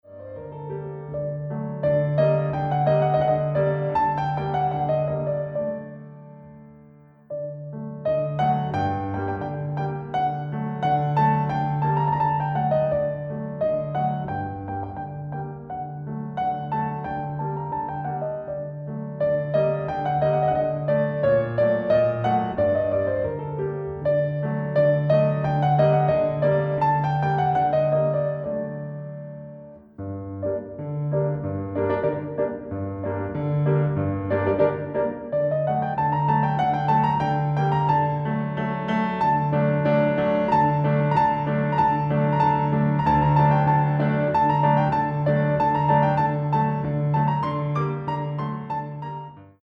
piano music
Persian classical music